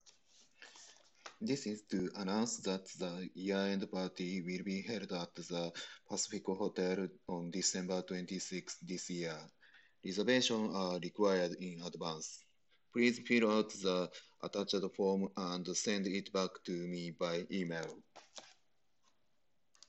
確認方法はそれぞれのヘッドセットをパソコンにBluetooth接続した状態で、Windows10に標準装備されているボイスレコーダに音声を録音して聞き比べてみます
今回、Bose SoundSport wireless headphonesとPlantronics explorer-100は特にノイズは無く会話するには十分な音質で、違いもほとんどわかりませんでした。